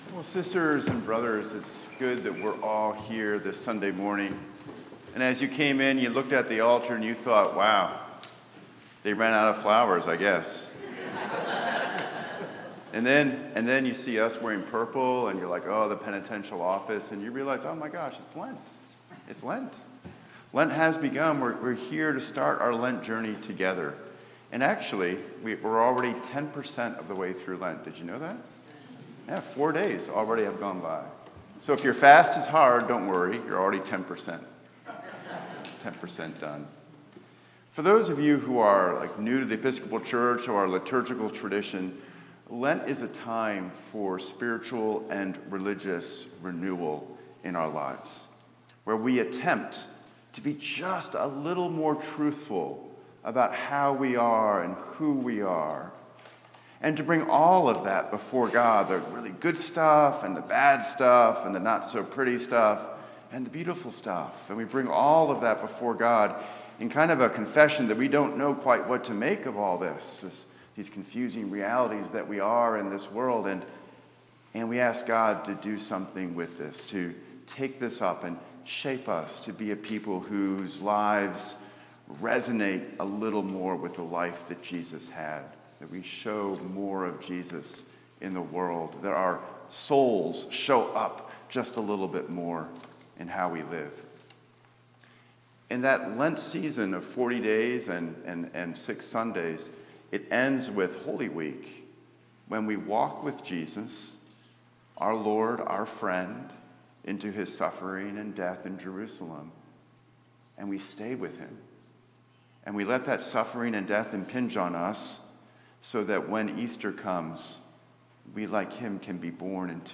St-Pauls-HEII-9a-Homily-22FEB26.mp3